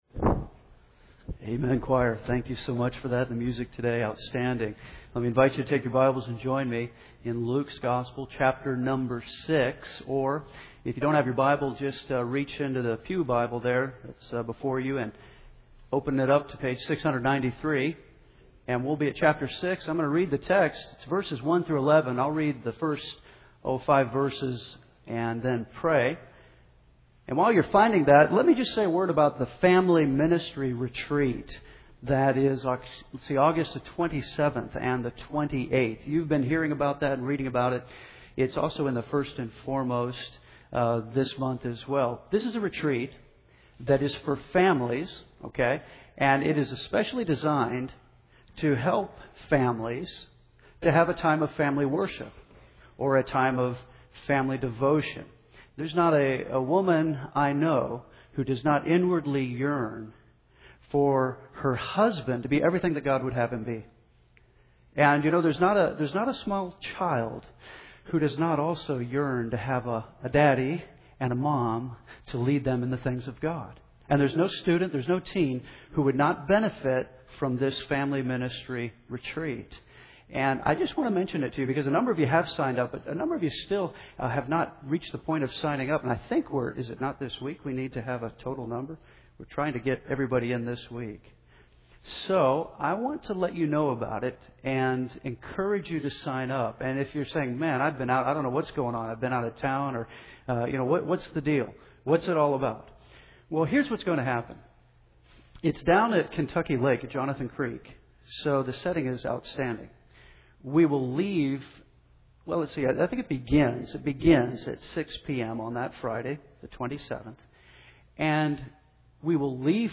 Henderson’s First Baptist Church, Henderson KY (8-1-10) (AM)